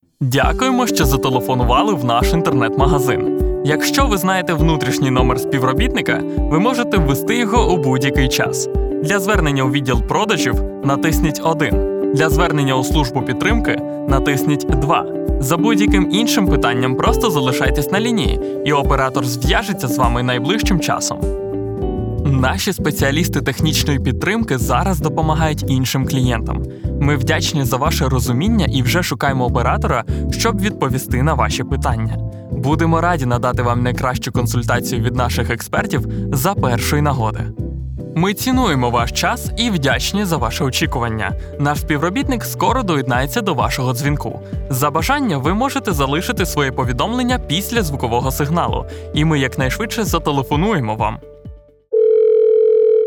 Comercial, Natural, Versátil, Amable, Travieso
Telefonía
Known for his reliable, friendly, conversational and playful voice.